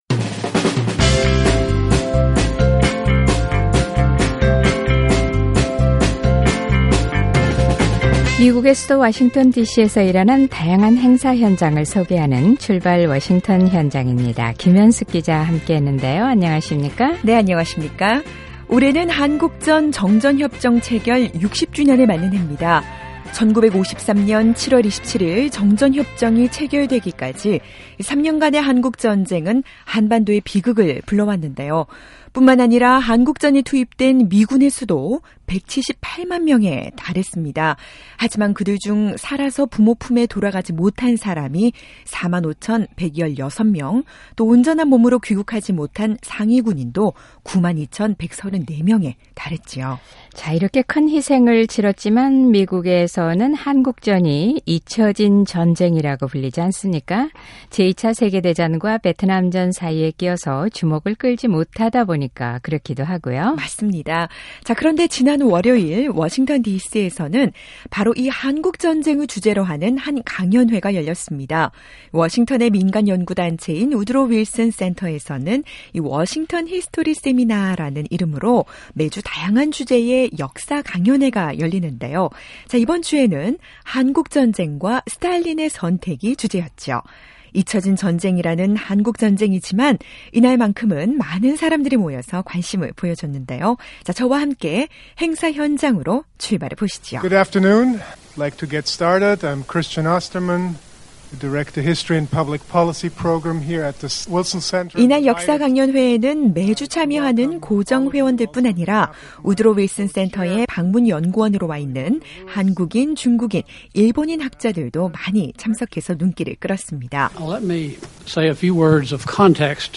하지만 이 전쟁엔 미국과 구소련, 중국의 결정적인 개입이 있었는데요, 워싱턴 디씨에서는 ‘한국전쟁과 스탈린의 선택’이라는 흥미로운 제목의 역사강연회가 열렸습니다. 과연 한국 전쟁에 있어서 스탈린의 선택과 다른 열강들의 결정은 어떤 영향을 가져왔을까요? 저와 함께 강연회 현장으로 출발해보시죠!